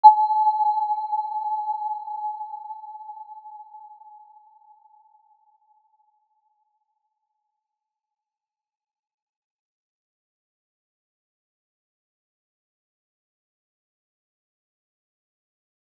Aurora-B5-mf.wav